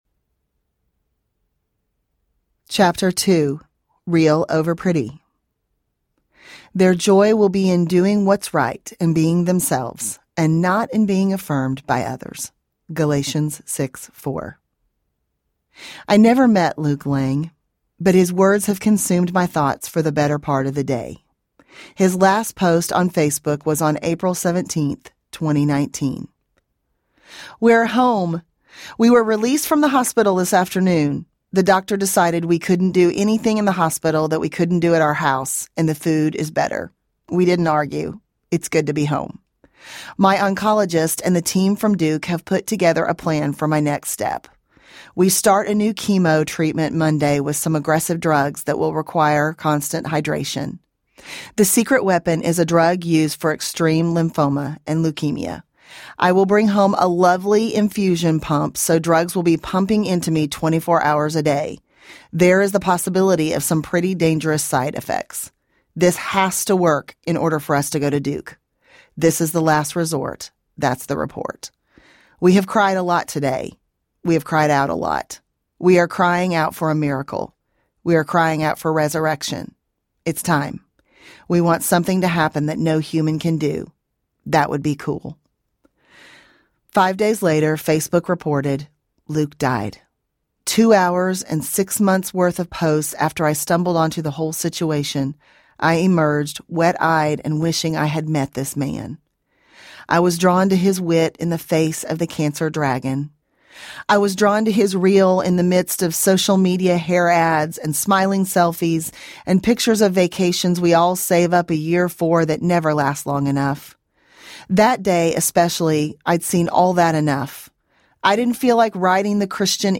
Jesus Over Everything Audiobook